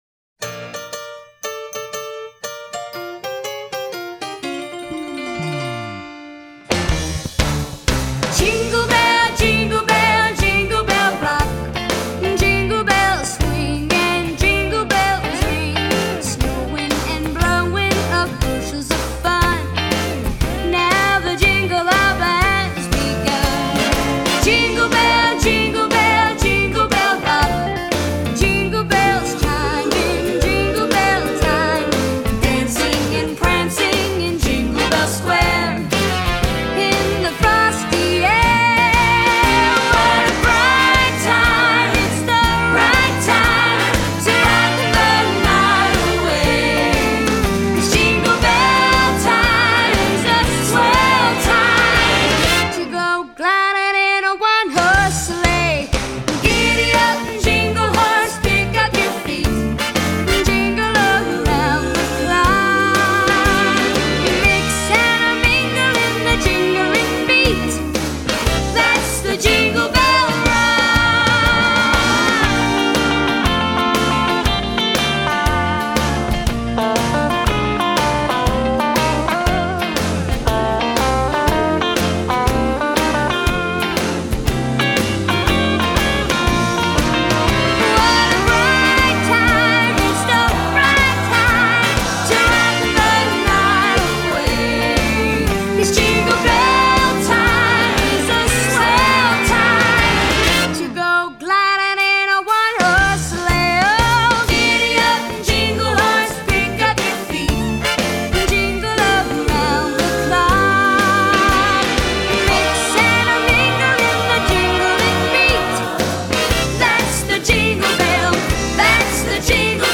音樂類型：西洋音樂